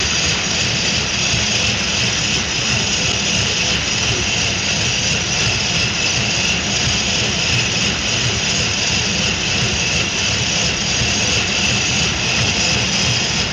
Paddle Boat Motor